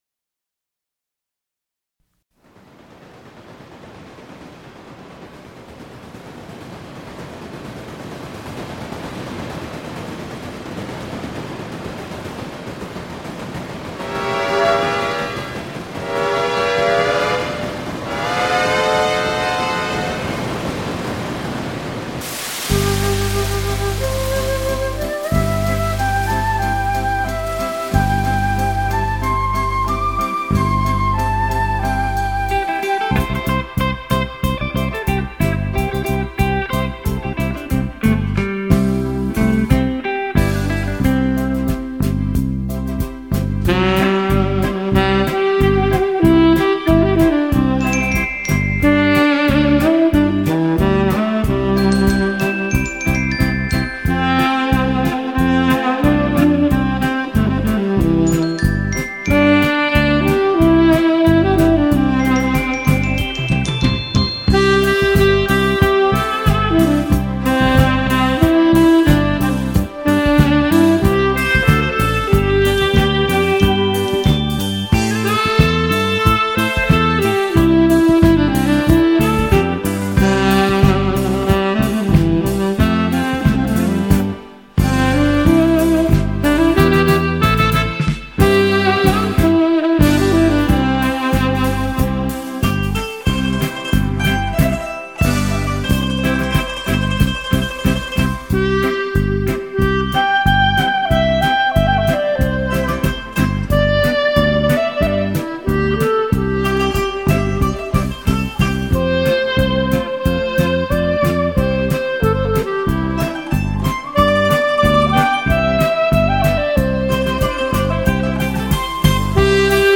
随着萨克斯风乐音的吹奏，
可让聆听的人能很自然的以轻松无压的情绪进入到音乐之中。
这轻柔音乐如水般拂过你的全身，